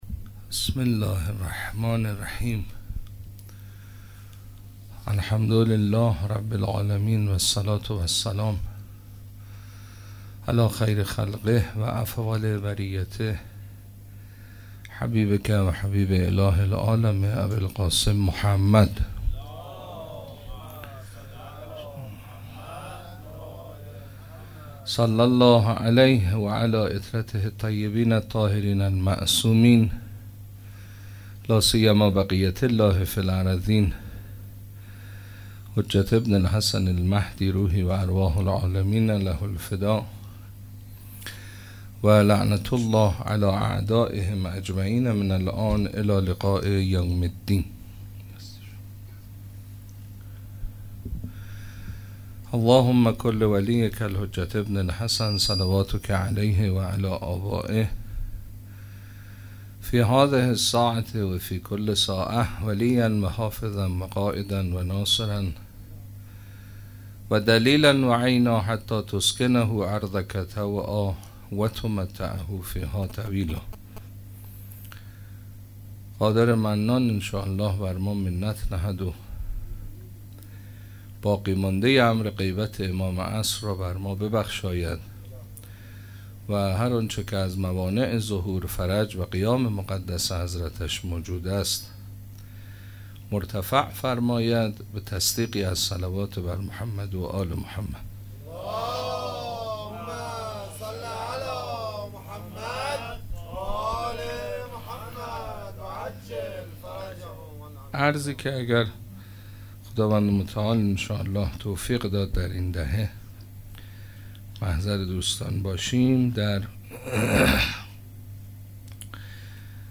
شب اول دهه سوم محرم 97 - سخنرانی